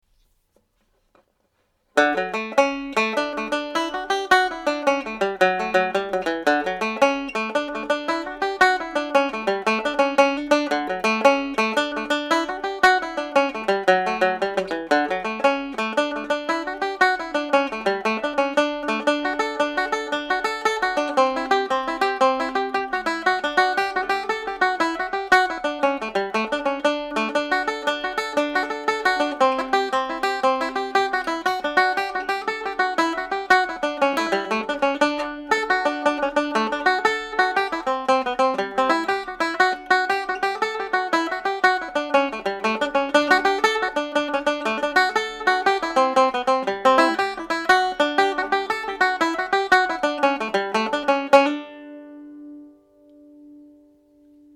Pure Banjo
Kitty’s Rambles played at normal speed